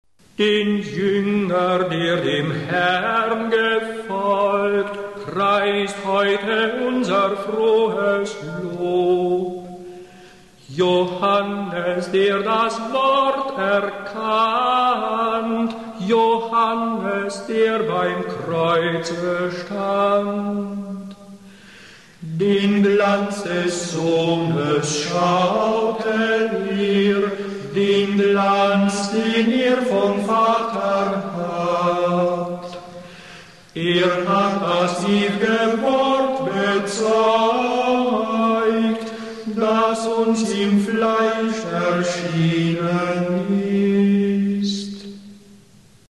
• Hymnus: